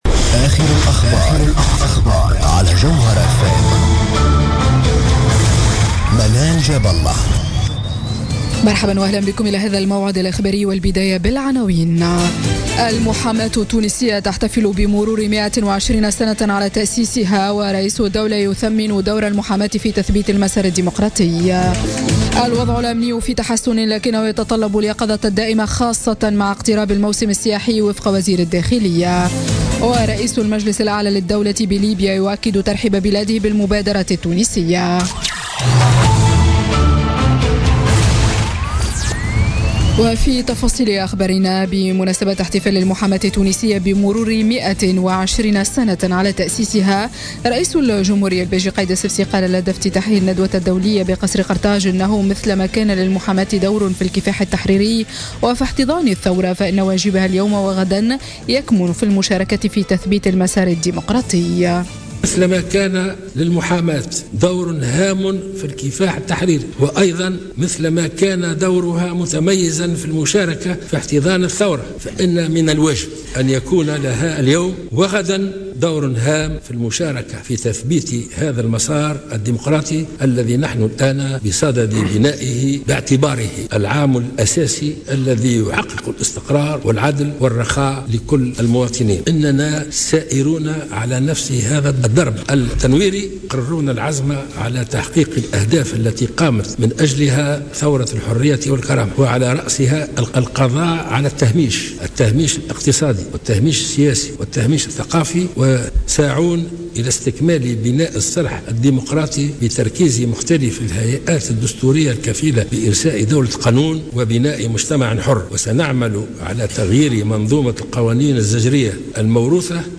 نشرة أخبار منتصف الليل ليوم الجمعة 24 فيفري 2017